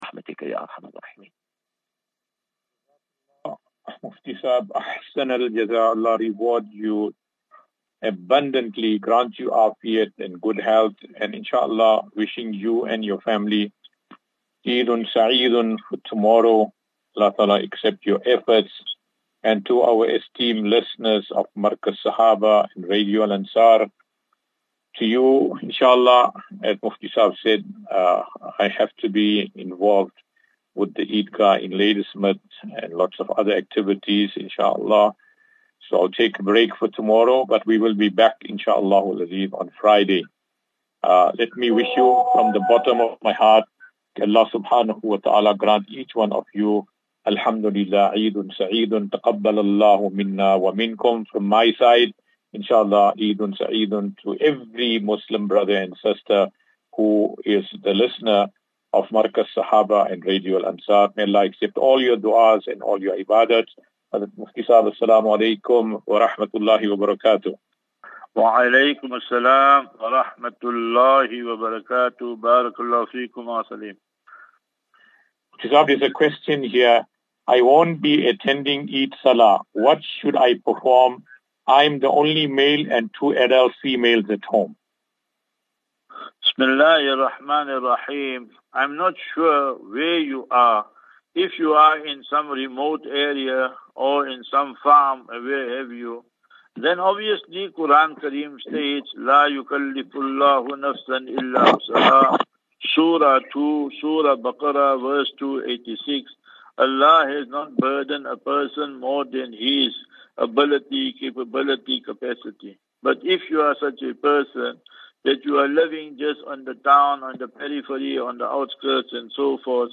As Safinatu Ilal Jannah Naseeha and Q and A 10 Apr 10 April 2024.